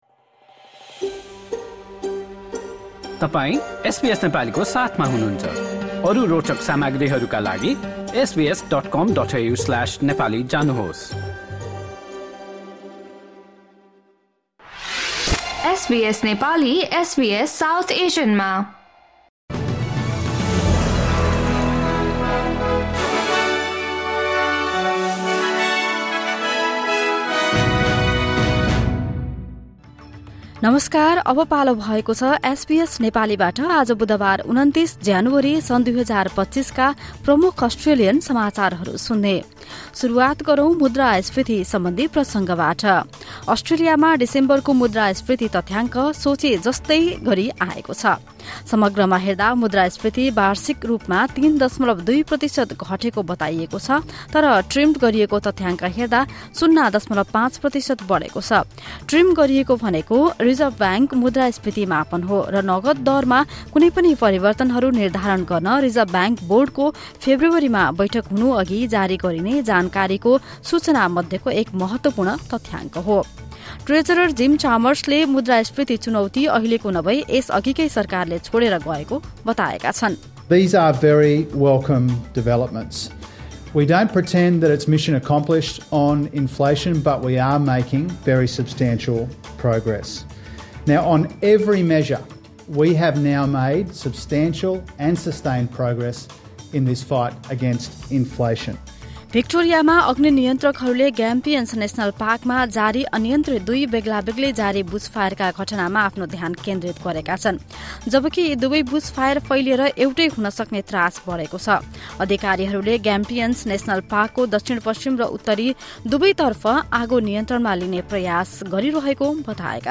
SBS Nepali Australian News Headlines: Wednesday, 29 January 2025